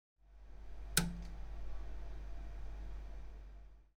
Verstärkeranschaltgeräusch
verstaerker